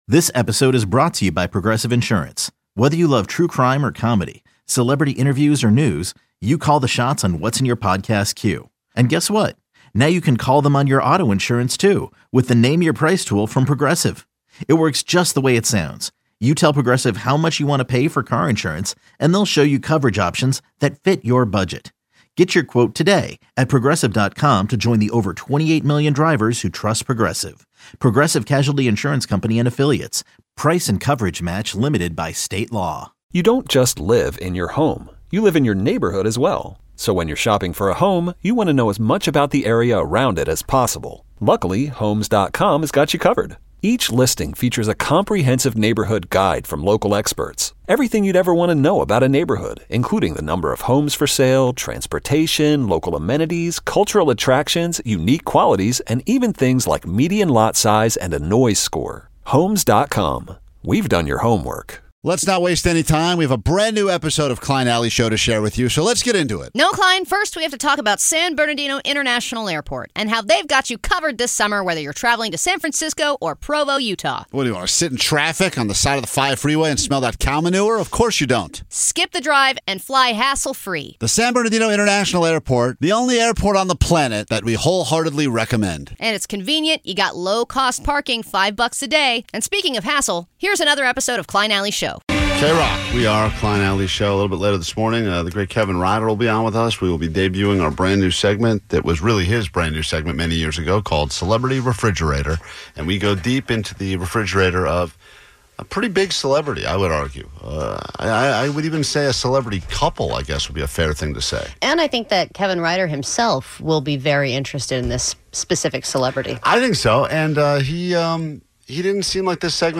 With a loyal, engaged fanbase and an addiction for pushing boundaries, the show delivers the perfect blend of humor and insight, all while keeping things fun, fresh, and sometimes a little bit illegal.